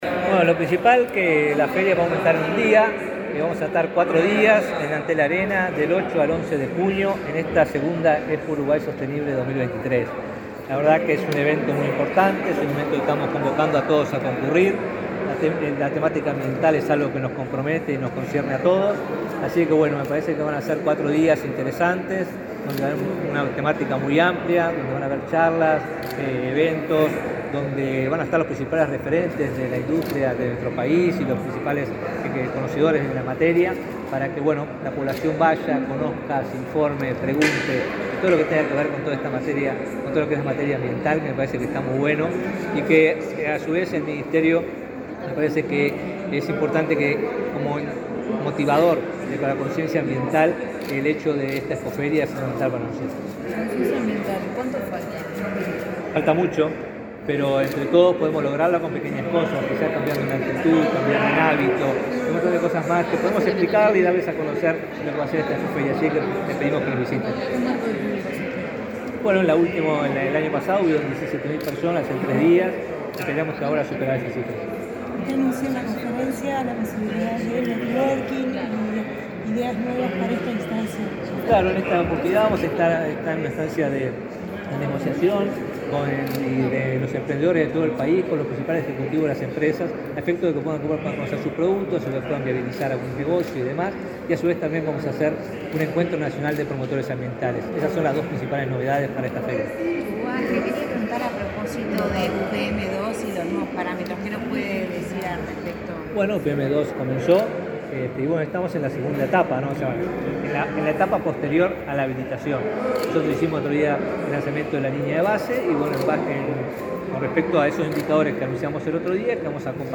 Declaraciones del ministro de Ambiente, Robert Bouvier
Luego dialogó con la prensa.